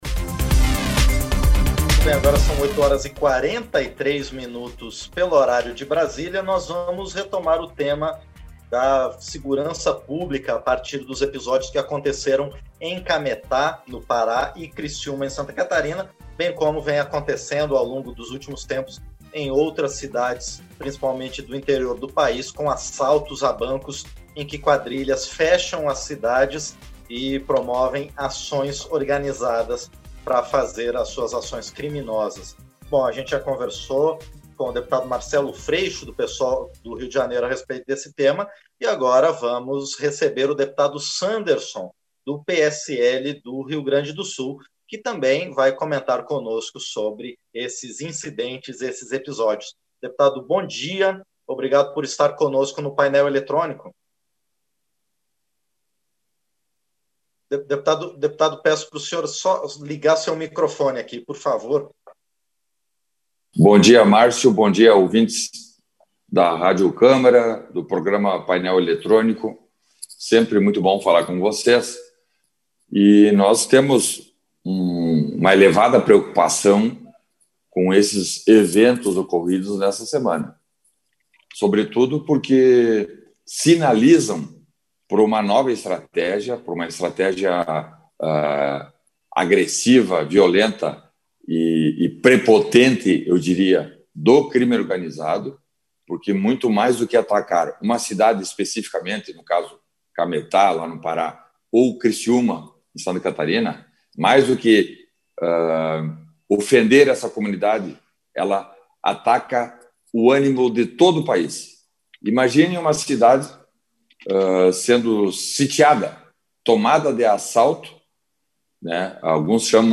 Entrevista - Dep. Sanderson (PSL-RS)